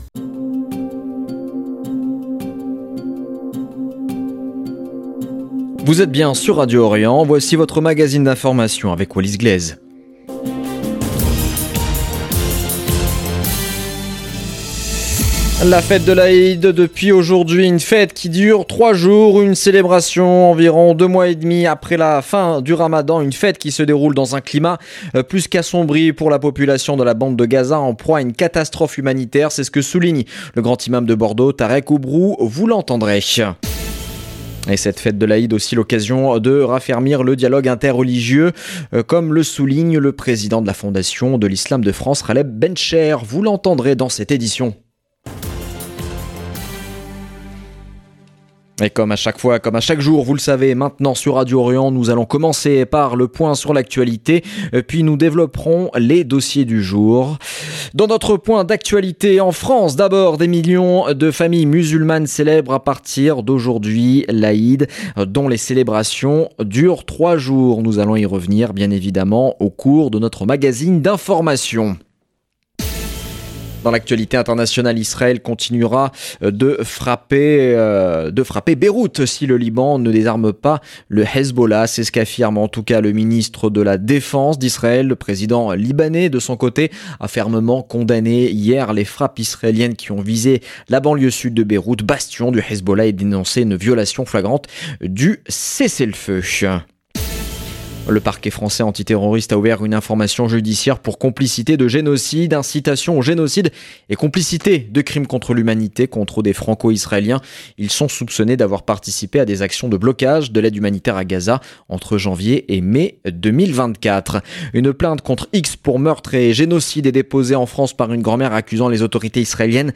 Magazine de l'information de 17 H00 du vendredi 6 juin 2025